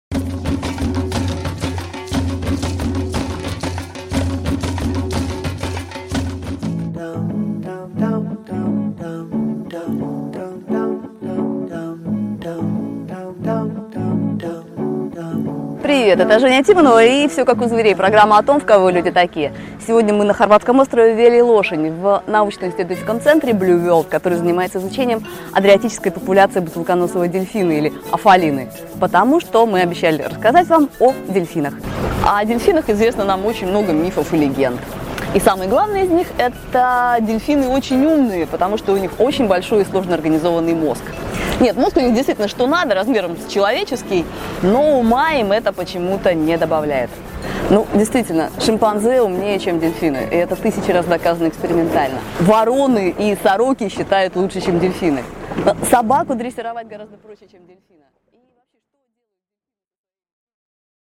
Аудиокнига Дельфин: животное-буддист | Библиотека аудиокниг
Прослушать и бесплатно скачать фрагмент аудиокниги